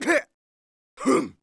fishing_fail_v.wav